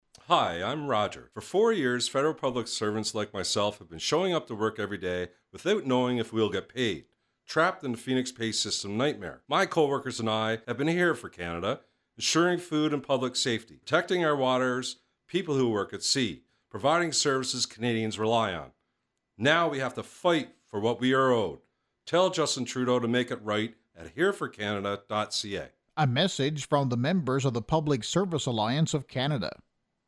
Annonce radio N.-É et T.-N.-L.473.02 Ko